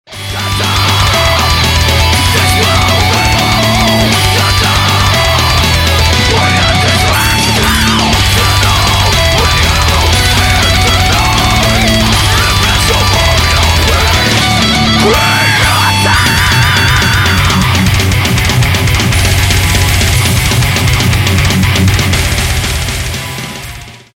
Громкие
Рок